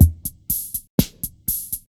• 122 Bpm Eighties Drum Beat E Key.wav
Free drum loop sample - kick tuned to the E note. Loudest frequency: 4250Hz
122-bpm-eighties-drum-beat-e-key-ncG.wav